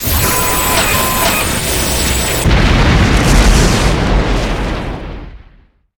miss.ogg